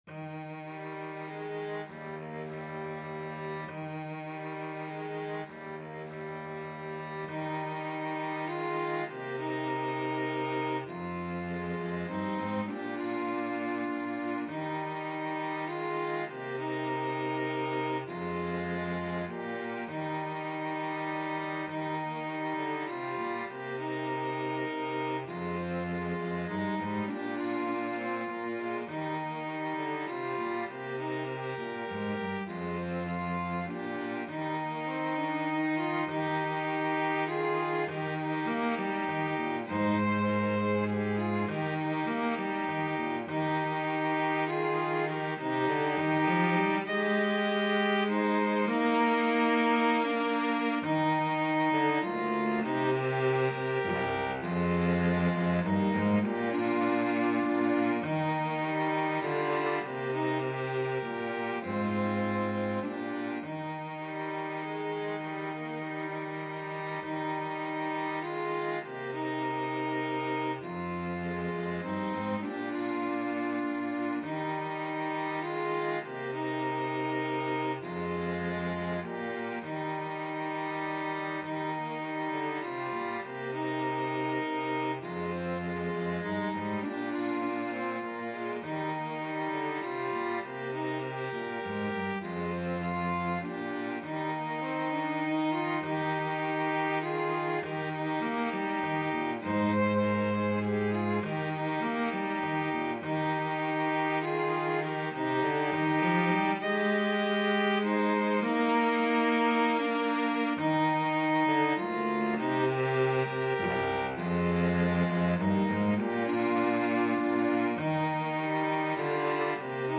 Violin 1Violin 2Cello
Gently. In the manner of a lullaby ( = c. 100)
3/4 (View more 3/4 Music)